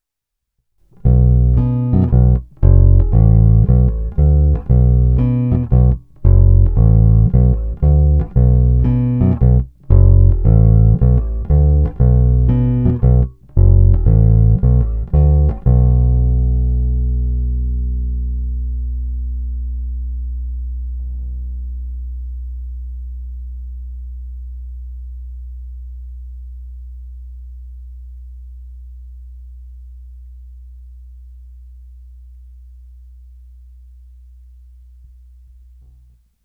Má hodně výrazné středy, je poměrně agresívní, kousavý.
Není-li uvedeno jinak, následující ukázky byly provedeny rovnou do zvukové karty a s plně otevřenou tónovou clonou, jen normalizovány, jinak ponechány bez úprav.
Hra mezi krkem a snímačem